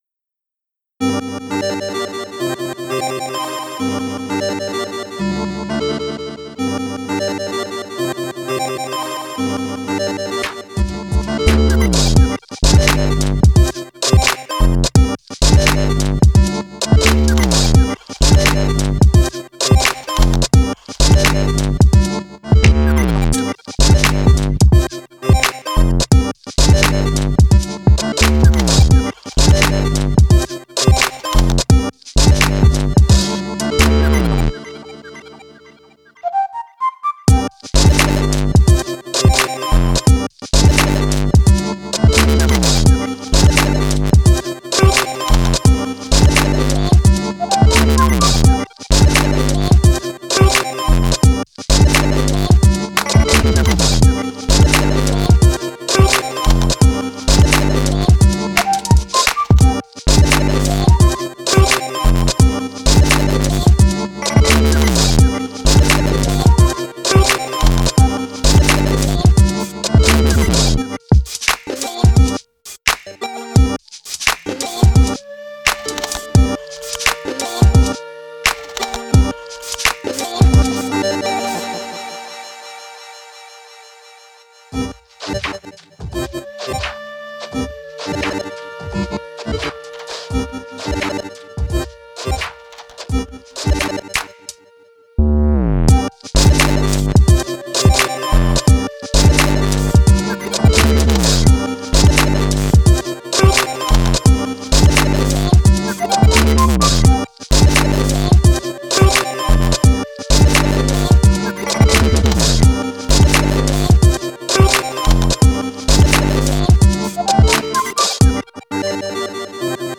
Broken electronic sounds now hip and hop.